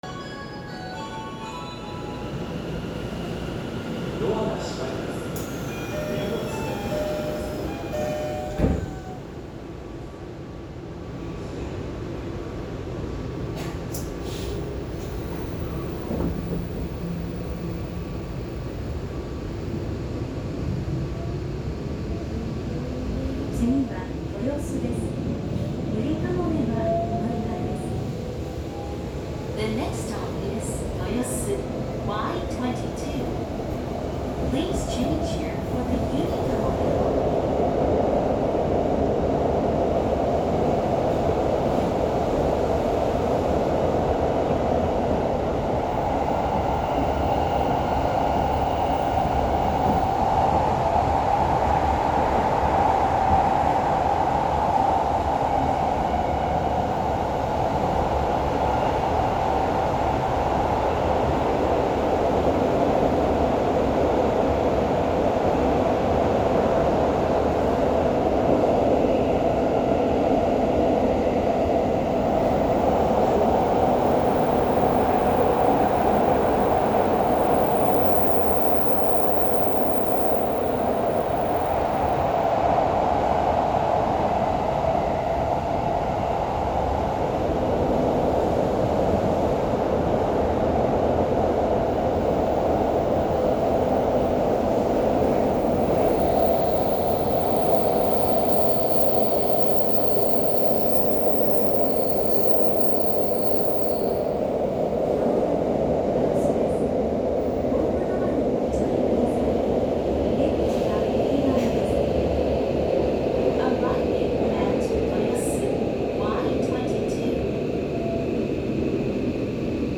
・17000系三菱PMSM走行音
10両編成は、近年の新車/機器更新車にありがちな三菱のPMSMを採用しており、起動音はほとんどありません。音鉄趣味的にはつまらない車両かもしれませんが、静粛性はその分非常に優れています。